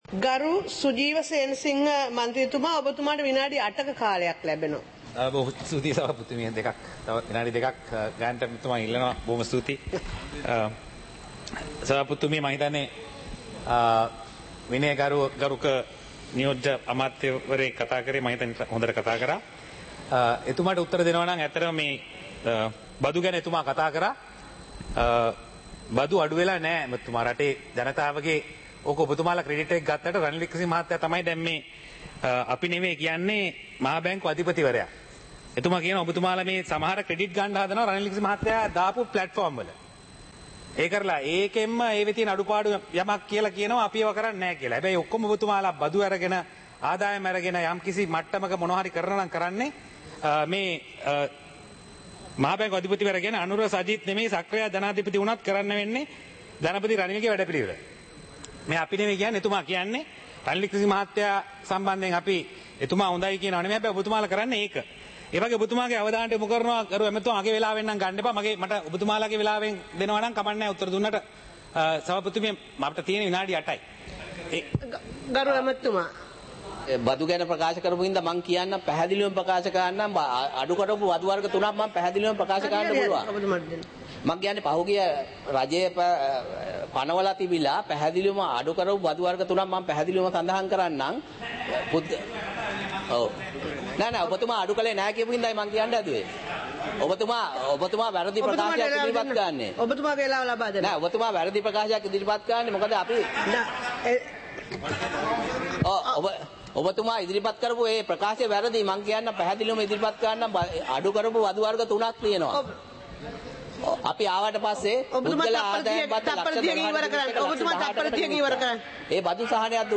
சபை நடவடிக்கைமுறை (2026-02-18)